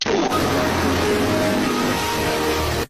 Goofy Ahh Windows Xp Startup Sound Button: Unblocked Meme Soundboard
Play the iconic Goofy Ahh Windows Xp Startup sound button for your meme soundboard!